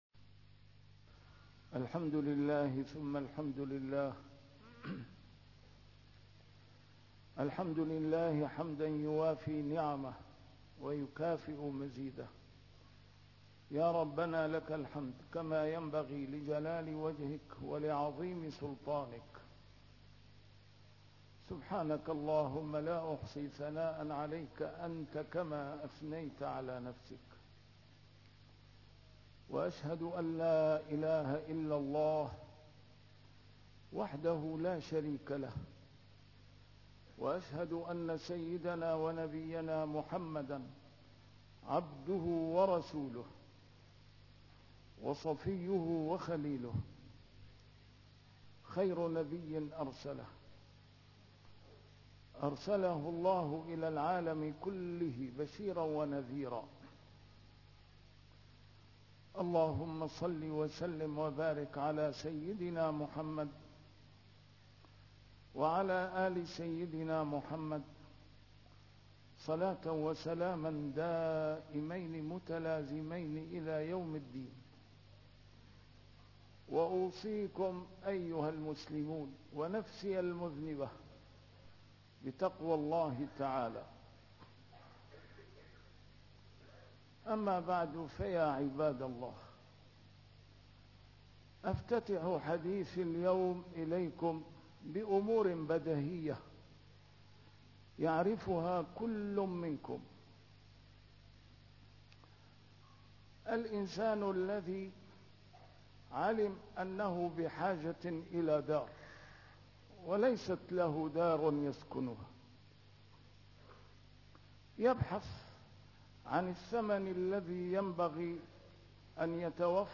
A MARTYR SCHOLAR: IMAM MUHAMMAD SAEED RAMADAN AL-BOUTI - الخطب - إن تنصروا الله ينصركم